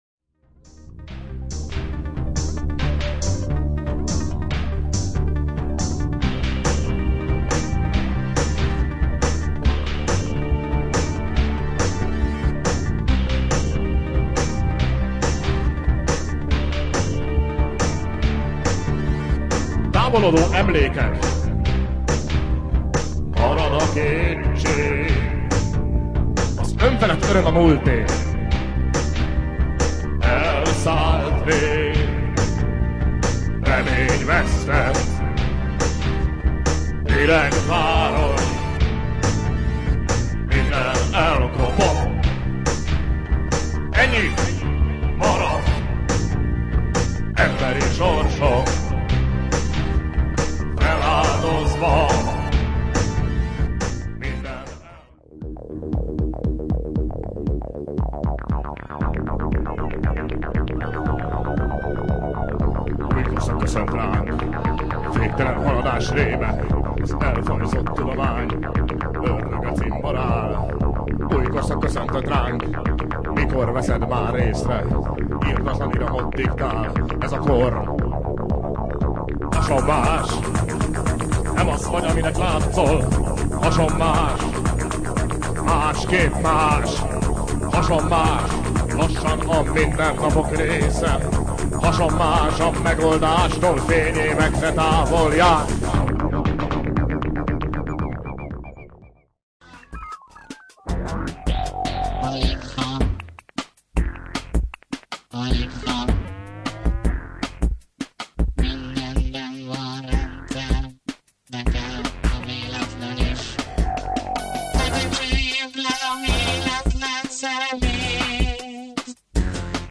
Recorded live in 2008.